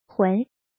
怎么读
hún